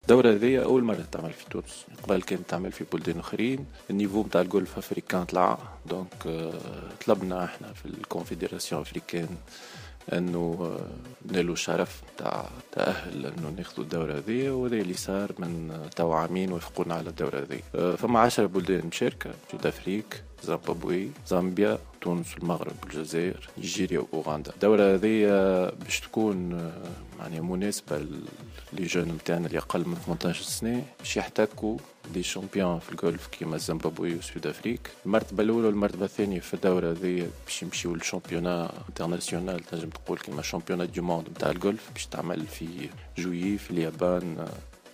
عقدت اليوم اللجنة المنظمة للبطولة الإفريقية للشبان للقولف "شبان" ندوة صحفية للحديث عن البطولة التي تحتضنها تونس للمرة الأولى وذلك من 21 إلى 27 مارس 2016 بملعب القولف القنطاوي بسوسة.